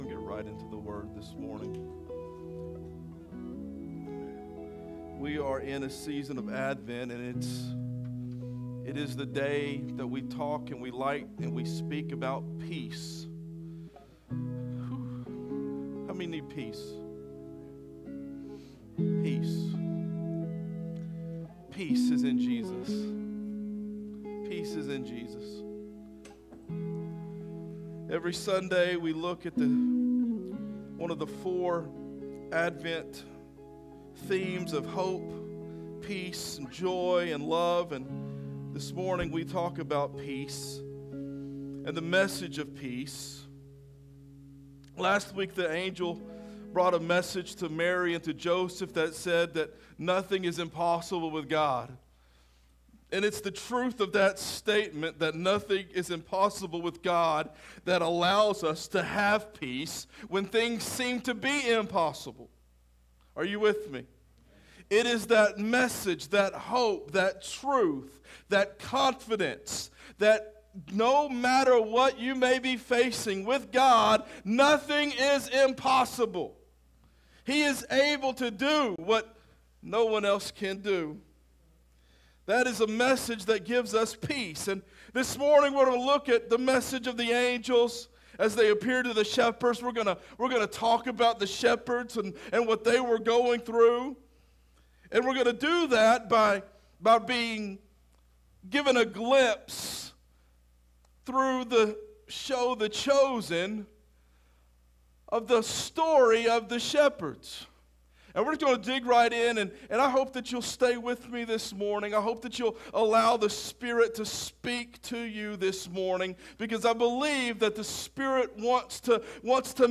Sermons | Real Life Community Church